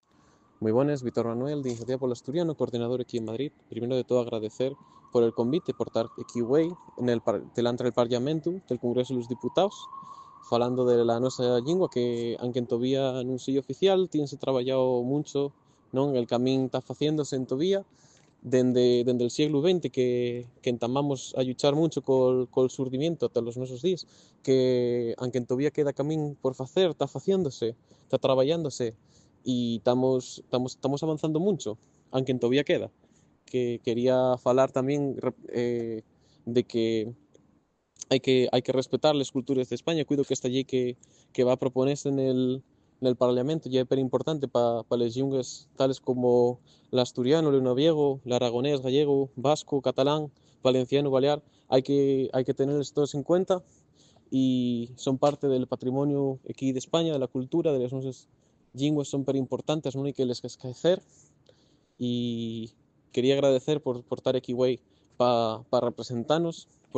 Iniciativa pol Asturianu participó güei na rueda de prensa convocada nel Congresu de los Diputaos pa presentar la Proposición de Llei Orgánica de garantía del plurillingüismu y de los drechos llingüísticos de la ciudadanía énte les instituciones del Estáu, un testu lexislativu que busca asegurar que toles llingües oficiales y propies del mesmu del Estáu cunten con un marcu de reconocencia y usu efectivu na alministración estatal.